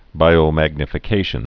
(bīō-măgnə-fĭ-kāshən)